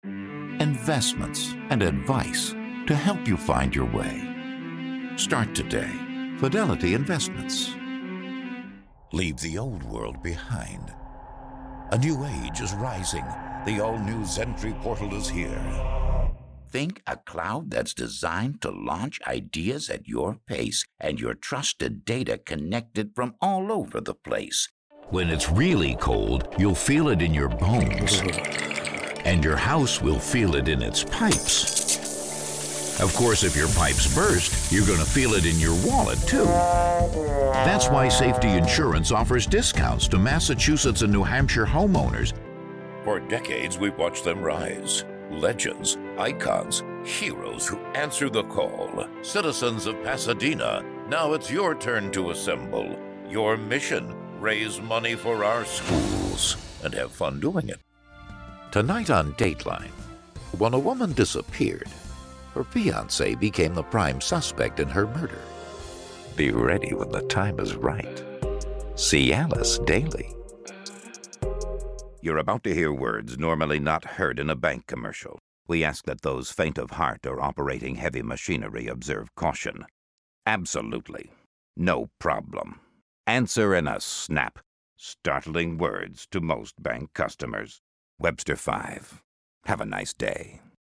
Versatile, Powerful, Directable
Commercial Demo
Middle Aged